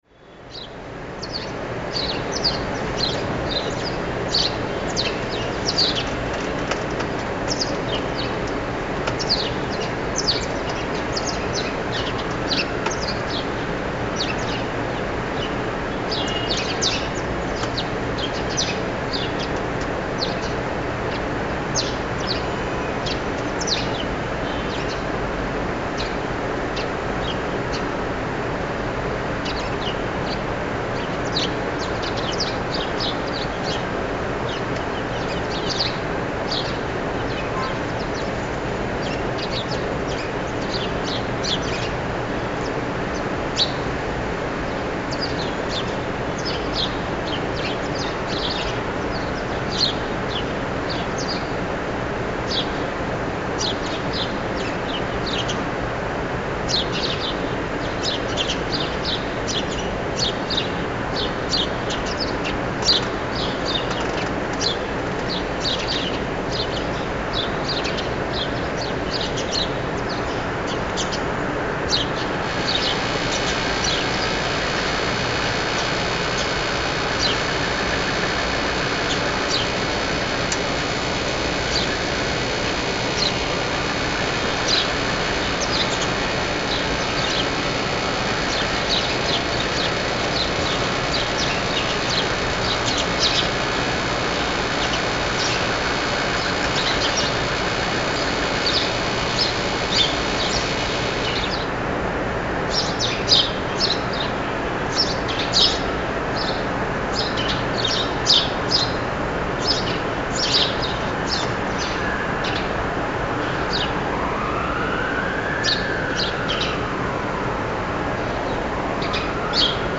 Birdsong and sirens, Riyadh
One of our earliest recordings from Saudi Arabia, sadly only able to be captured on a mobile phone, but evocative of the soundscape nonetheless. In 40 degree heat outside the Marriott hotel, we can hear police sirens in the distance, the heavy drone of Riyadh's constant traffic, and a light chorus of birdsong in the hotel garden.